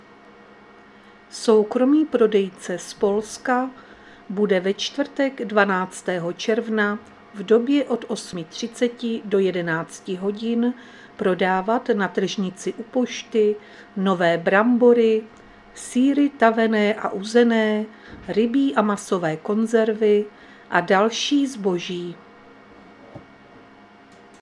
Záznam hlášení místního rozhlasu 11.6.2025
Zařazení: Rozhlas